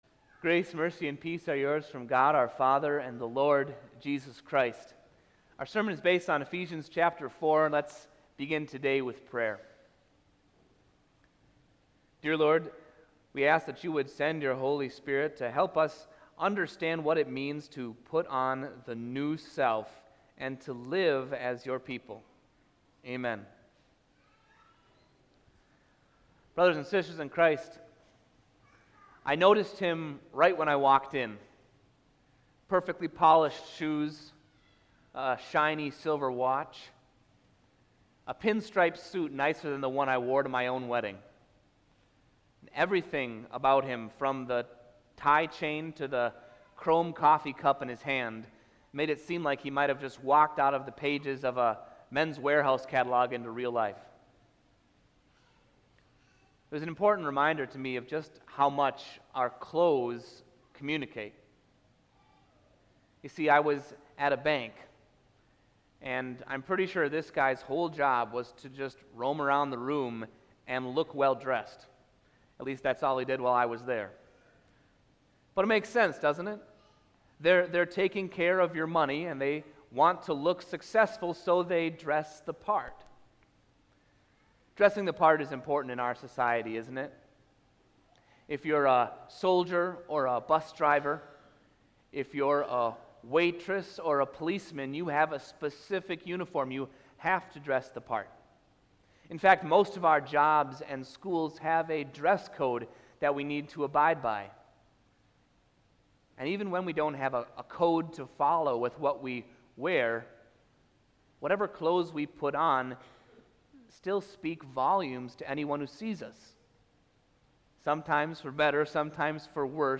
AudioSermonAugust52018.mp3